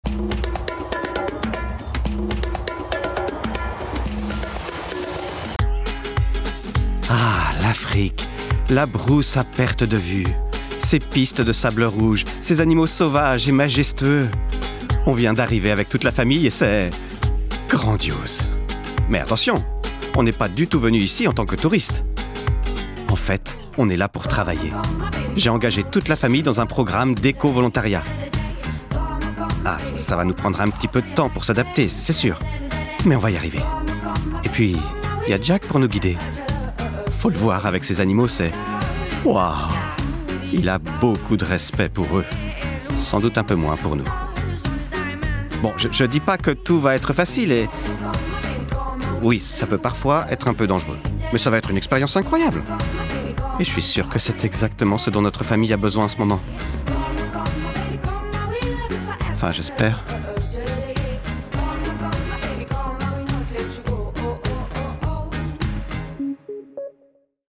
Démo voix
- Baryton